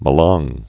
(mə-läng)